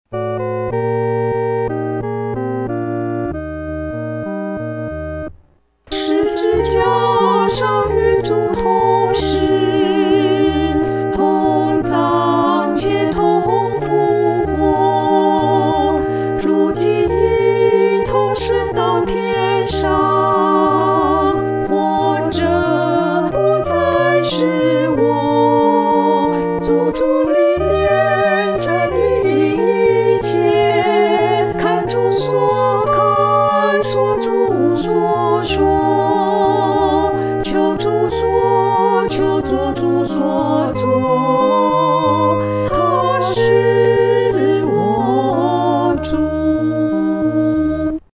合唱（女二声部）